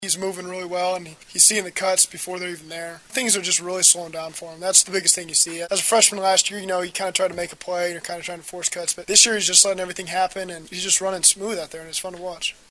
The following are audio links to postgame interview segments with Husker players and coaches after Nebraska's 56-0 win over Troy.
Quarterback Zac Taylor